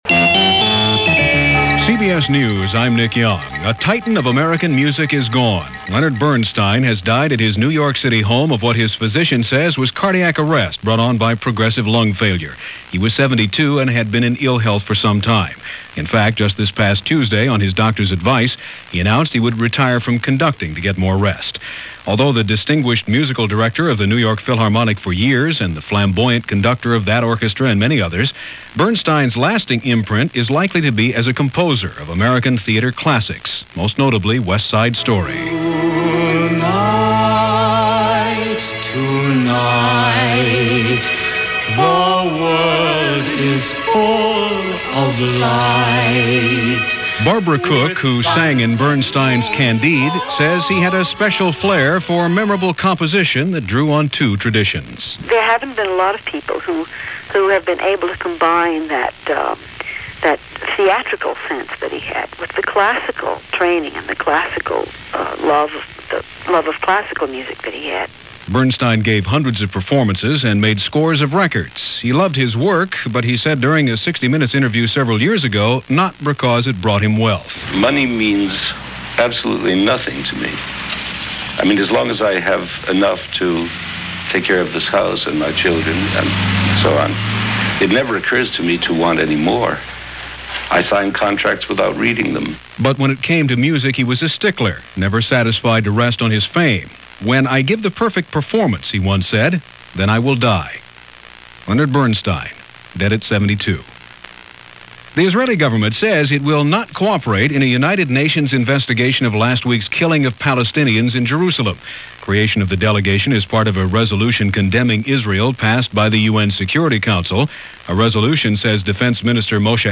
And while we were absorbing the news on Leonard Bernstein,  that’s a little of what else went on this October 14th in 1990, as presented by CBS Radio’s Hourly News.